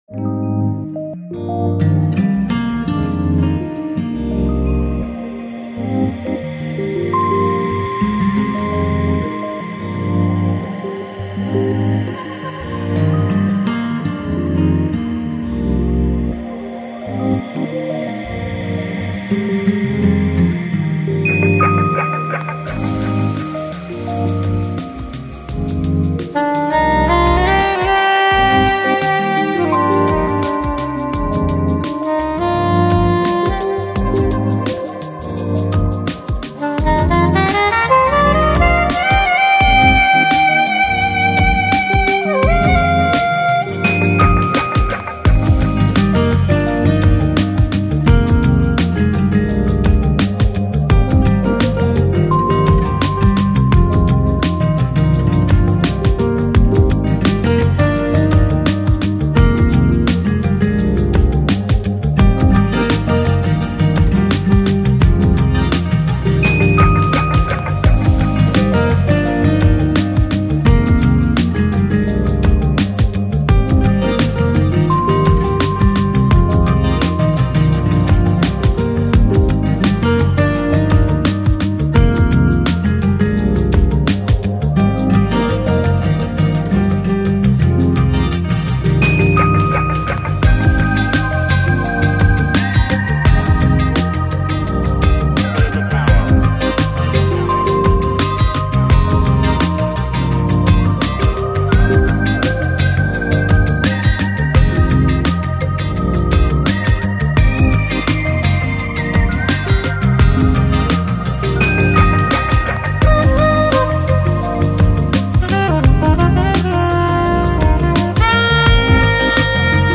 Жанр: Lounge, Chill Out, Downtempo